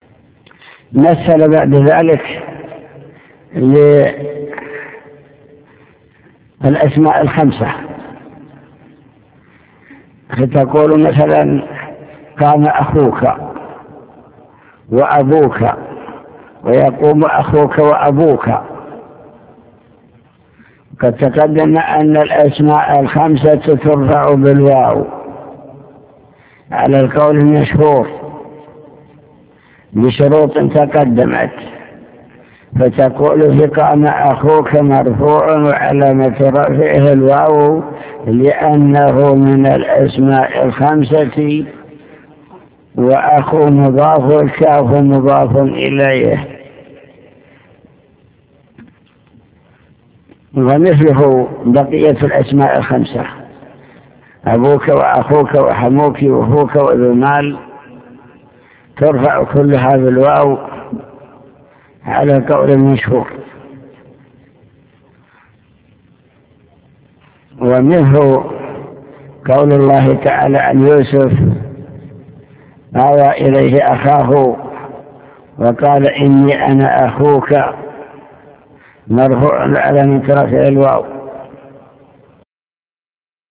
المكتبة الصوتية  تسجيلات - كتب  شرح كتاب الآجرومية باب الفاعل أقسام الفاعل أنواع الفاعل الظاهر